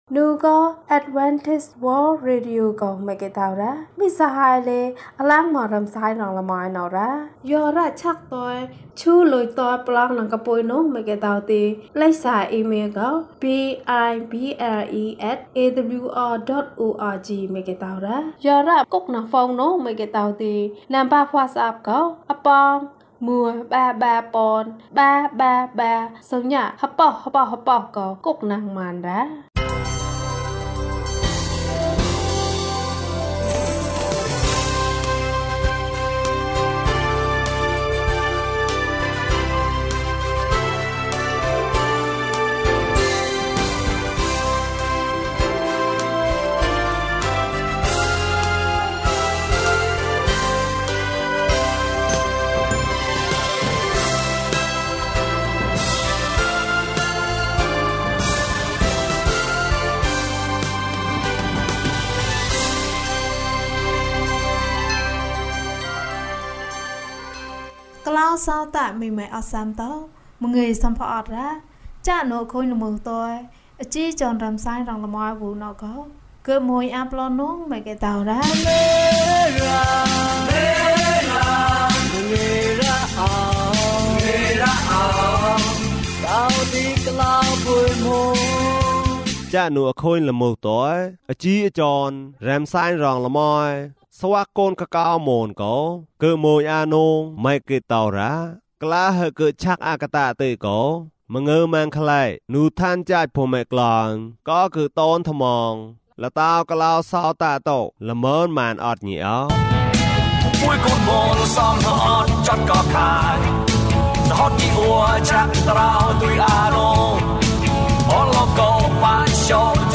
ငါသူ့ကိုယုံတယ်။၀၂ ကျန်းမာခြင်းအကြောင်းအရာ။ ဓမ္မသီချင်း။ တရားဒေသနာ။